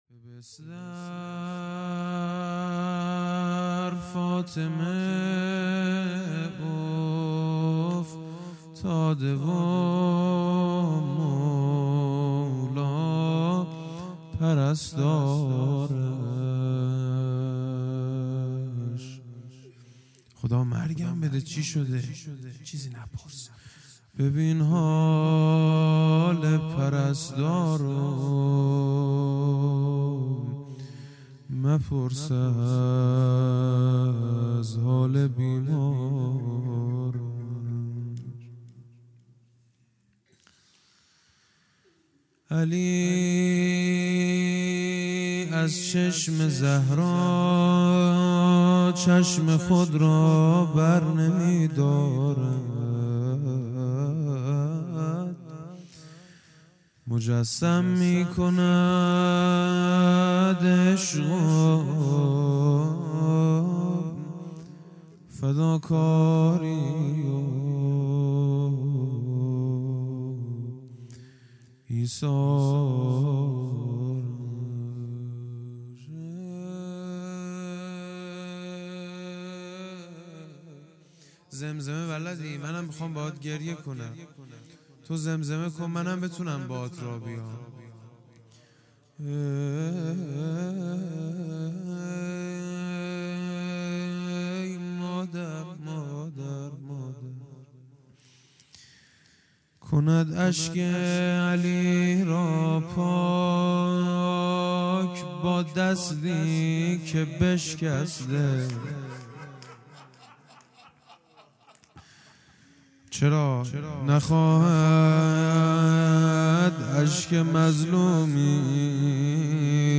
هیئت دانشجویی فاطمیون دانشگاه یزد - روضه
جلسه هفتگی